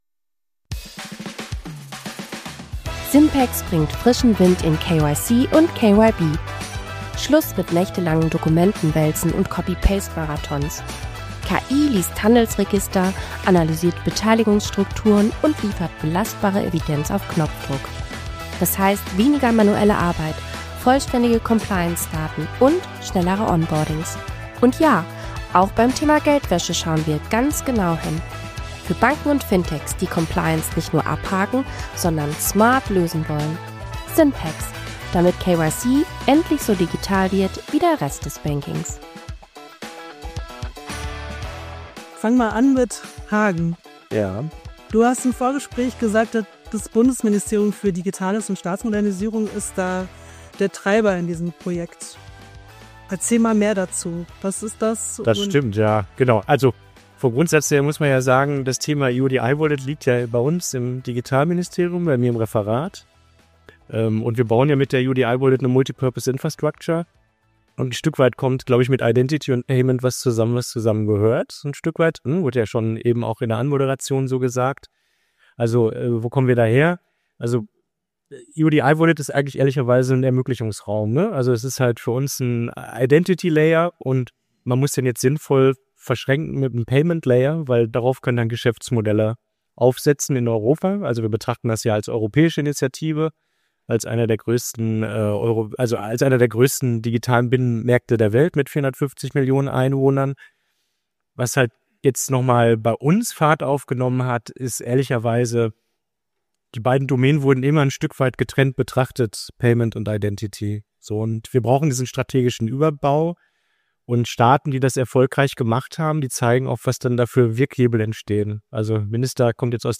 Identity und Payment wachsen zusammen und schaffen einen neuen Vertrauensstandard. Beim PEX-Panel diskutierten EU, Staat und Branche über Europas digitale Infrastruktur der Zukunft.